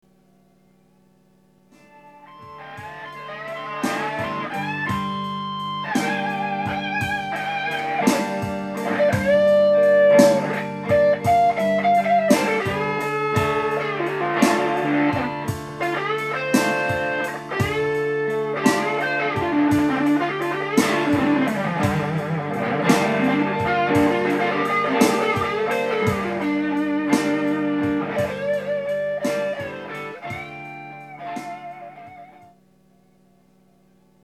669KB（34秒）→いいバッキングにより拙いソロがヨイショされた場合